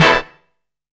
TOP HIT.wav